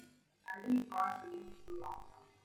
描述：SNR 0dB噪声信号的去噪信号